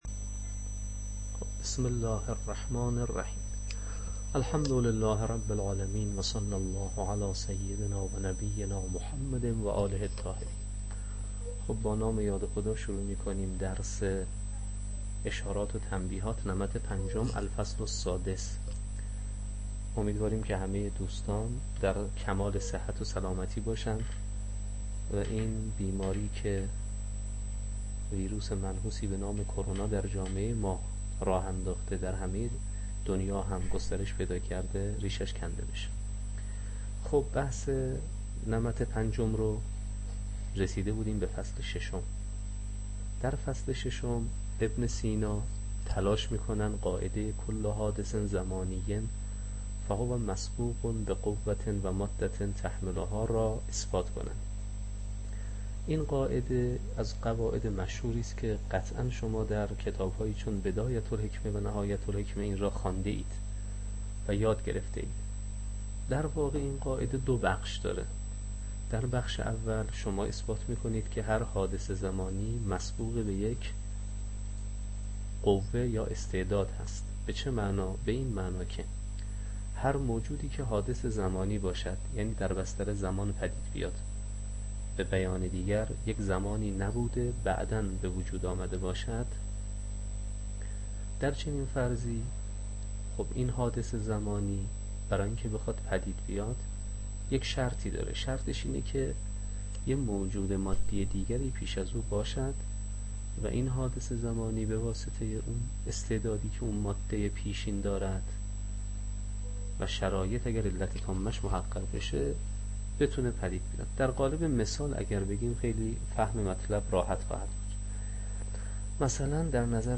شرح اشارات و تنبیهات، تدریس